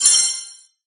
emit_coins_01.ogg